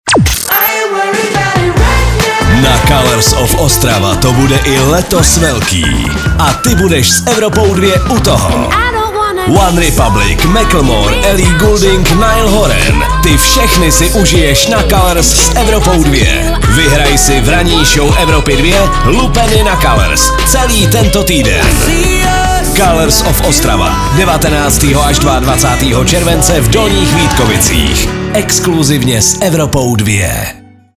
liner-colours-of-ostrava.mp3